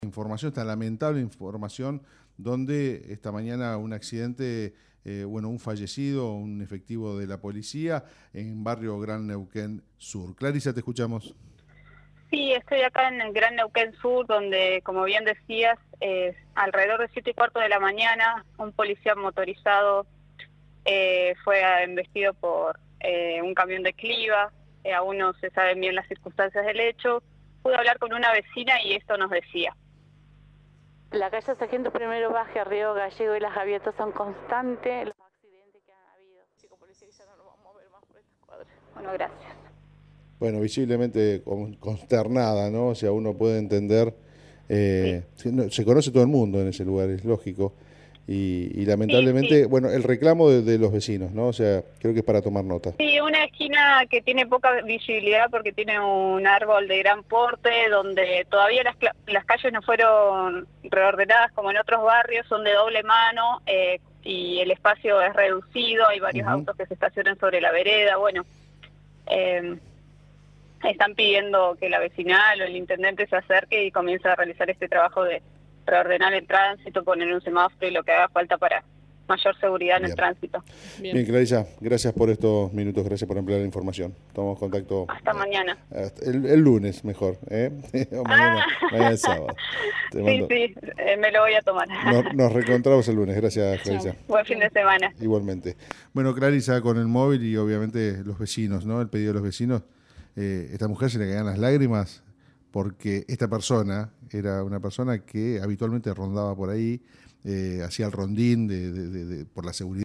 En diálogo con el móvil de La Red Neuquén , una vecina manifestó la necesidad de que la zona tenga un semáforo.
Además, lamentó entre lagrimas la perdida.